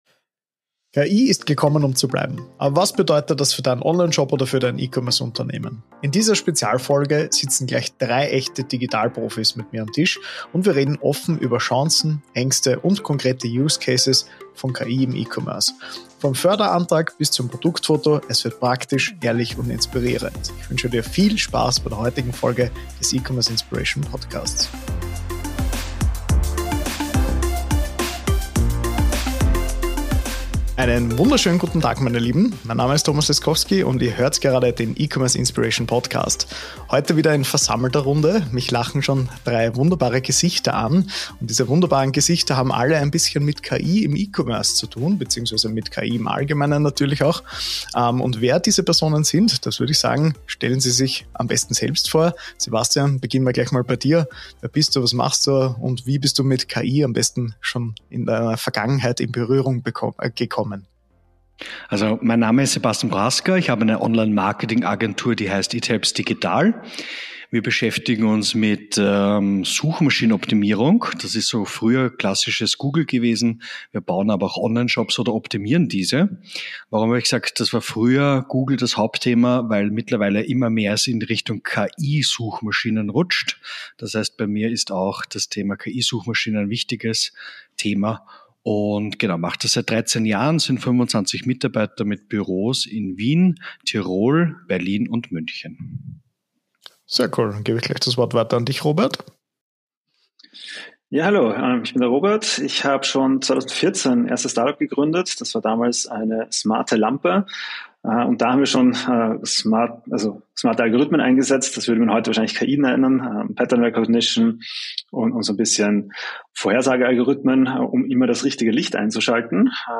#044: KI-Revolution im E-Commerce: Einblicke vom Roundtable ~ E-Commerce Inspiration Podcast